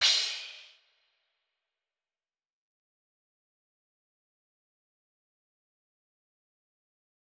Crashes & Cymbals
DMV3_Crash 10.wav